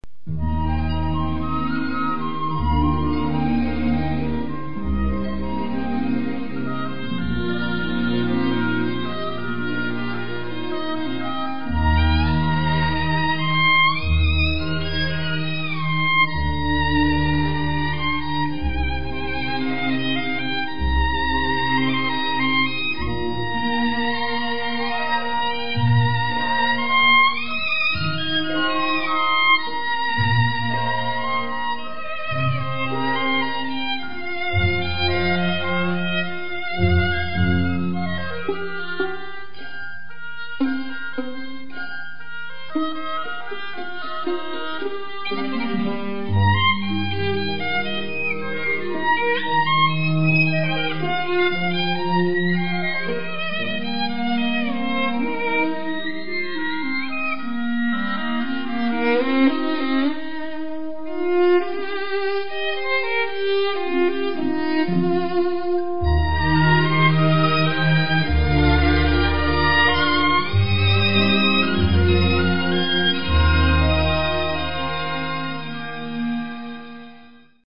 [23/3/2014]春天的歌 小提琴 花香鼓舞